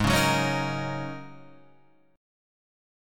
G#9sus4 chord